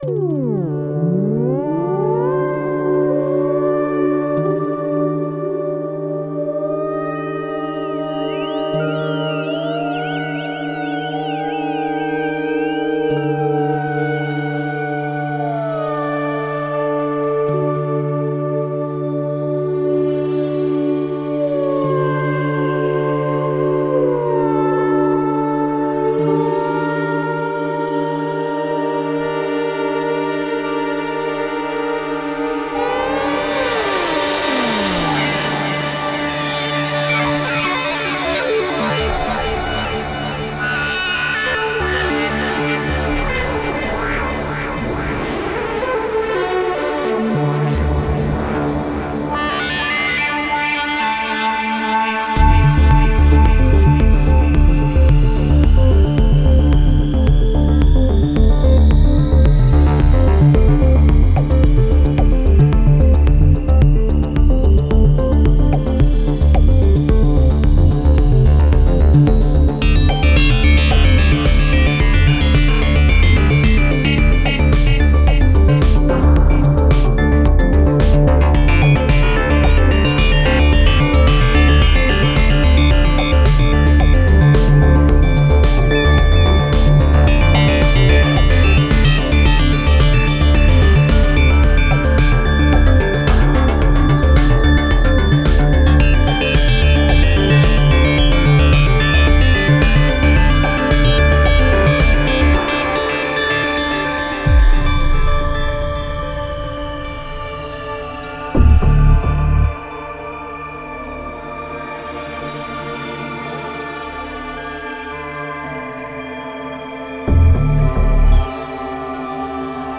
cinematic korg opsix presets by synthmorph
Step into a world of rich textures and intricate soundscapes where cold metallic sounds merge seamlessly with soft, inspiring harmonics, offering a sonic experience that both enchants and excites.
Experience how glistening, icy textures and intricate, modulated sequences combine effortlessly with expressive depth, forming a versatile and evocative sound palette.
13 Motion, 20 Keyboard, 9 Pad/Strings, 5 Bell/Decay, 5 Bass, 1 Lead, 21 Arp/Seq & 6 SFX/Perc
Synthmorph - Opsix Arctic Shimmer - DEMO.mp3